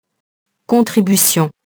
contribution [kɔ̃tribysjɔ̃] nom féminin